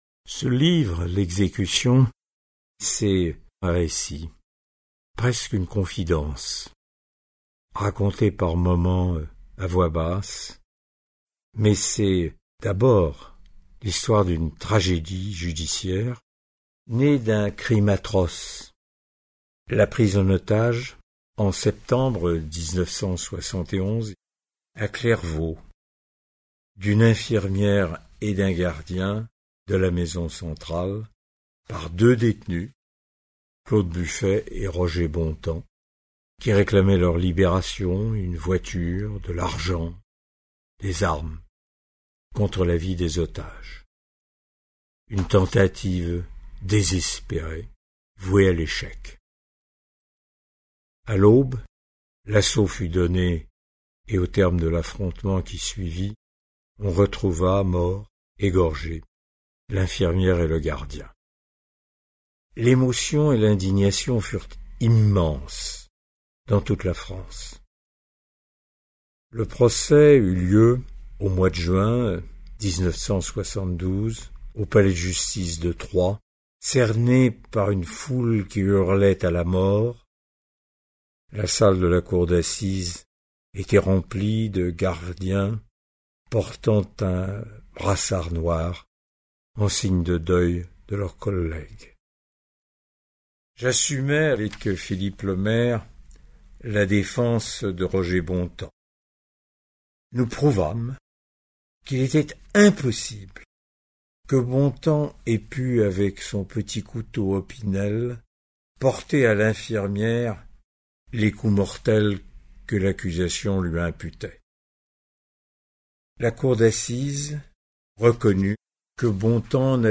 Lire un extrait Disponible en ebook L'Exécution 5 , 49 € Robert Badinter L'Exécution Audiolib Date de publication : 2009 Avec une présentation inédite lue par l'auteur.
Pierre Viansson-Ponté, Le Monde, 3 octobre 1973 Avec une sensibilité hors du commun et la force de son engagement, Charles Berling nous fait revivre, après avoir tenu le rôle dans le téléfilm de façon mémorable, le premier combat de Robert Badinter contre la peine de mort.